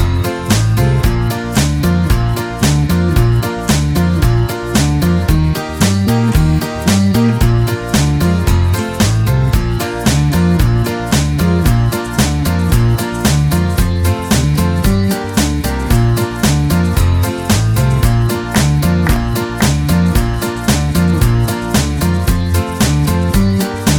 Minus Guitars Pop (1960s) 3:08 Buy £1.50